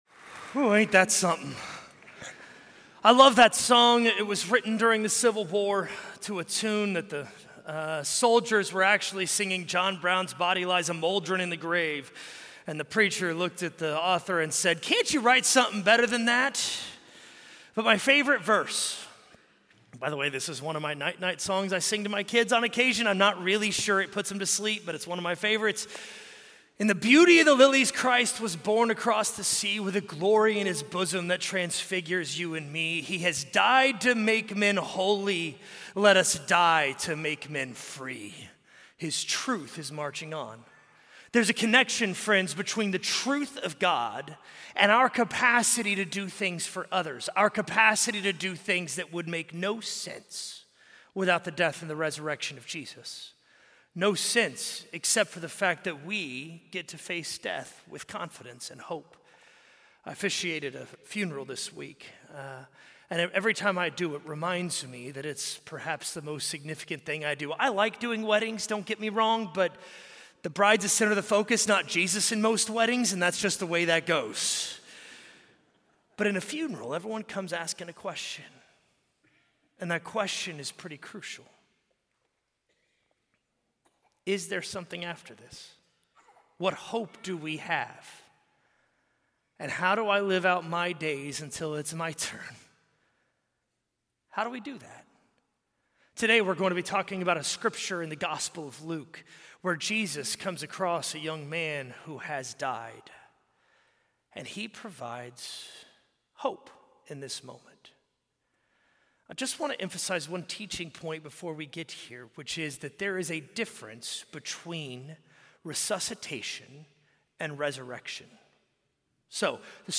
A message from the series "Jesus Is Good News."